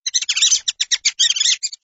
звук мыши
Прикольный звук мышиного писка на смс